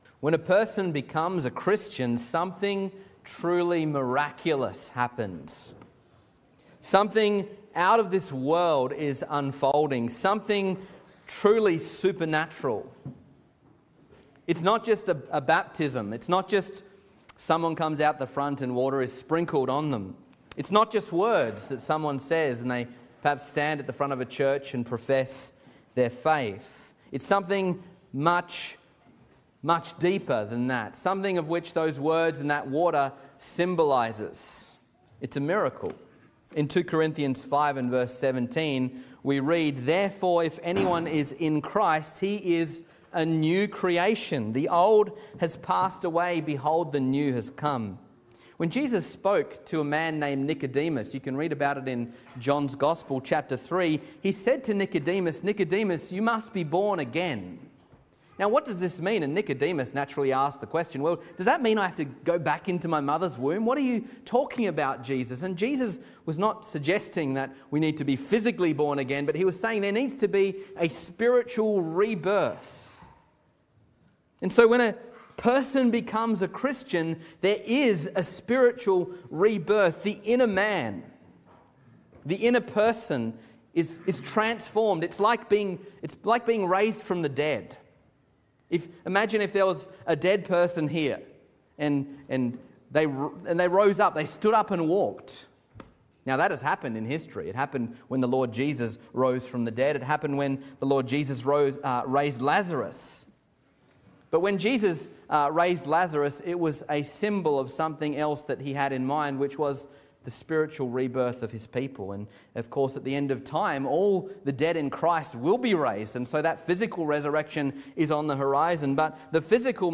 Sermons | Reformed Church Of Box Hill